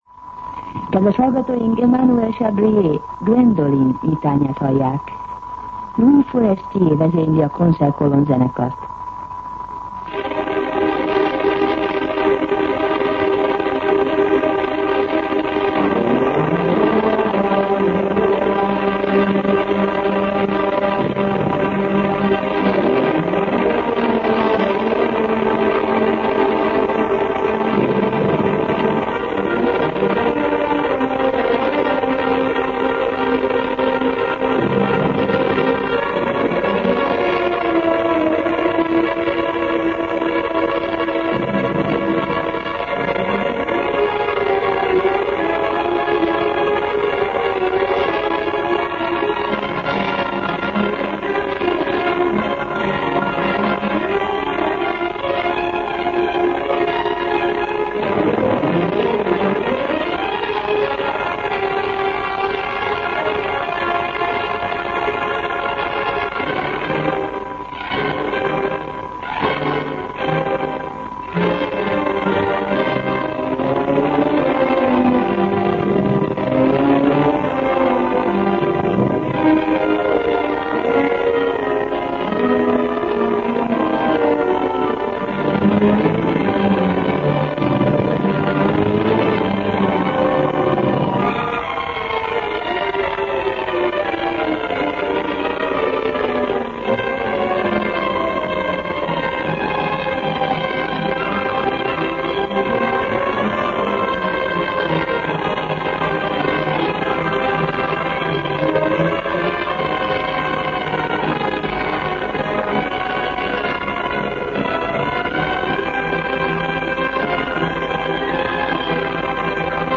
zenekart